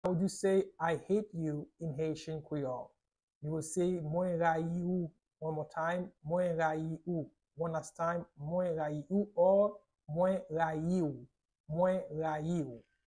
How to say “I hate you” in Haitian Creole - “Mwen rayi ou” pronunciation by a native Haitian Creole Teacher
“Mwen rayi ou” Pronunciation in Haitian Creole by a native Haitian can be heard in the audio here or in the video below: